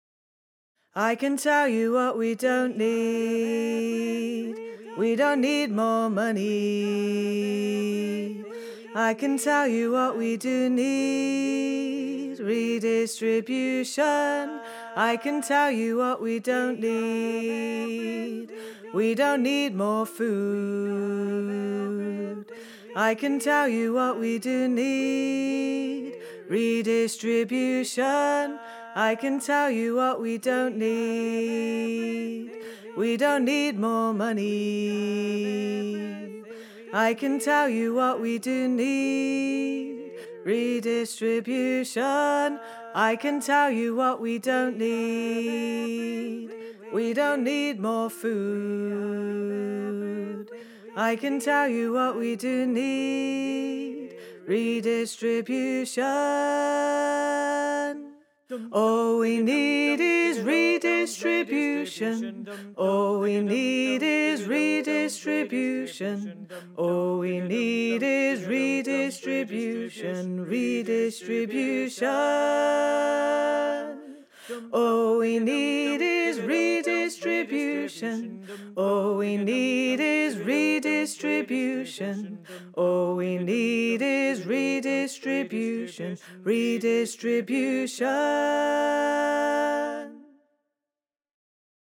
Tenor:
redistribution_learning-tracks_tenor.wav